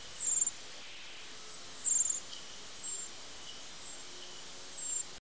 pishuha-amerikanskaya-certhia-americana-114kb.mp3